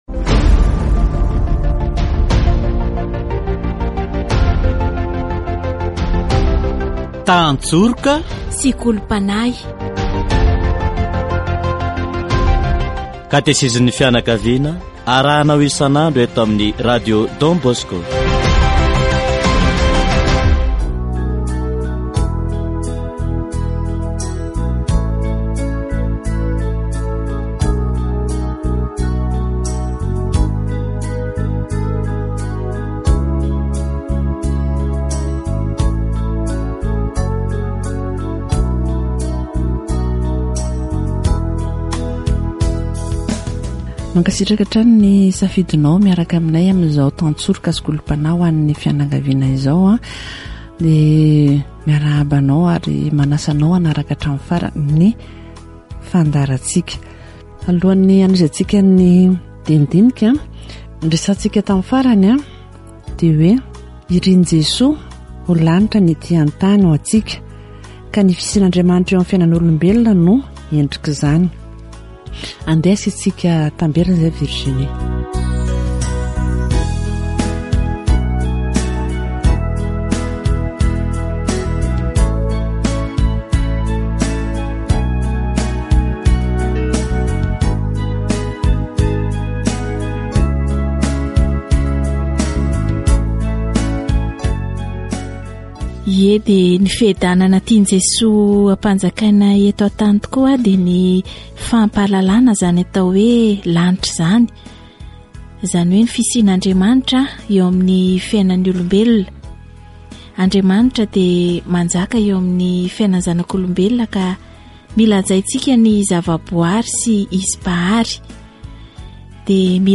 Katesizy momba ny fiaraha-monina sy ny fiadanana ety an-tany